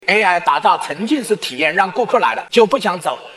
用于病毒式喜剧内容的搞笑男声生成器
引人入胜的搞笑男声AI
文本转语音
喜剧节奏
幽默语调